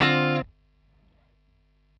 Dm7_11.wav